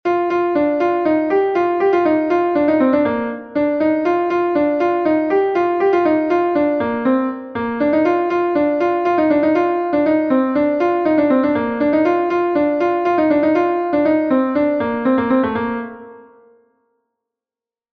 Gavotenn Kerguzul is a Gavotte from Brittany